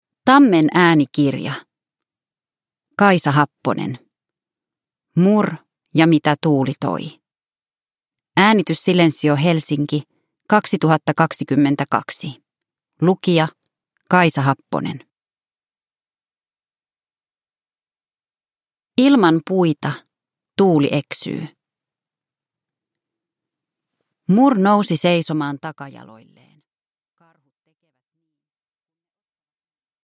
Mur ja mitä tuuli toi – Ljudbok – Laddas ner